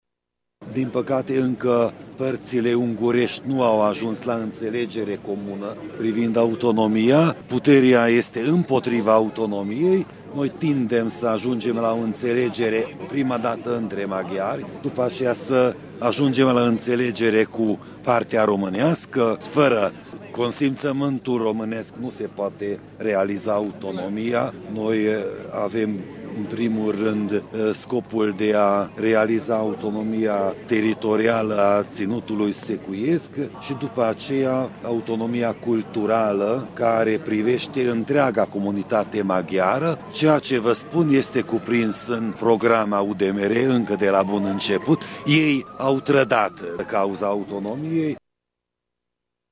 Pastorul Tőkés László a declarat pentru Radio Tîrgu-Mureș că reprezentanții UDMR au trădat cauza autonomiei: